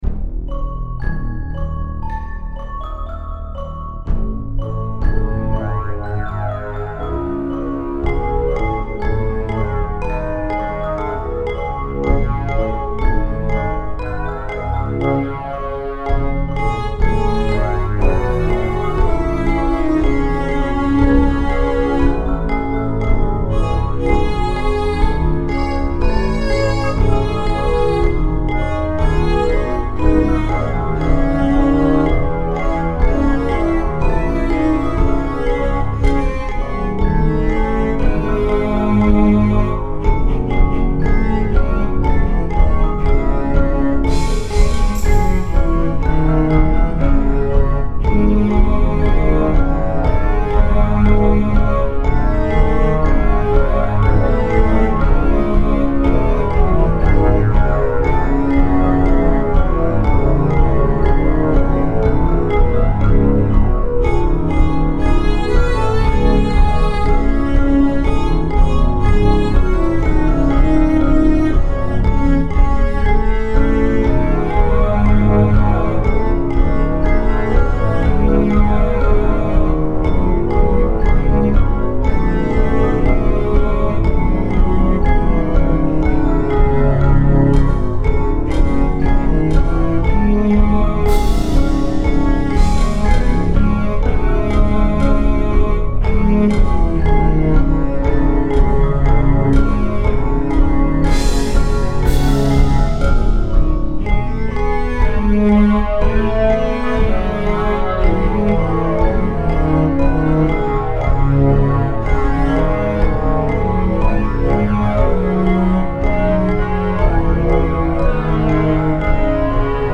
Posted in Classical, Dubstep, Other Comments Off on